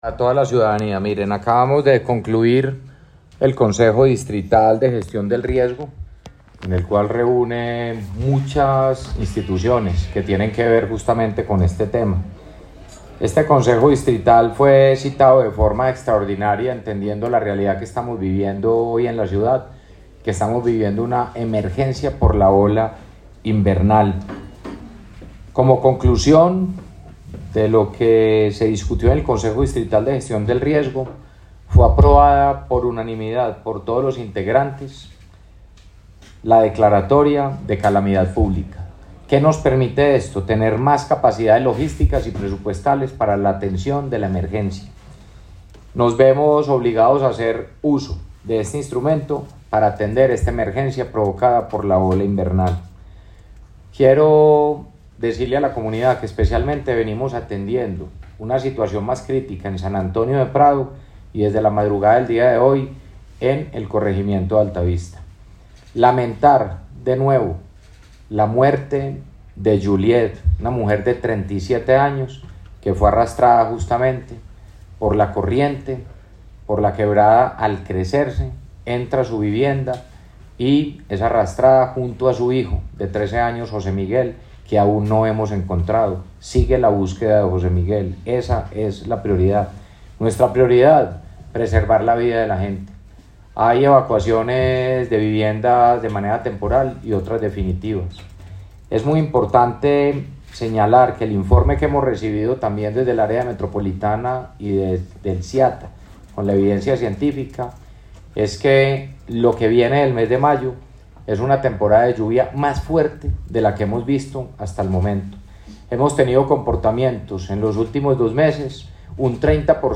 Audio-Declaraciones-del-alcalde-de-Medellin-Federico-Gutierrez-Zuluaga.mp3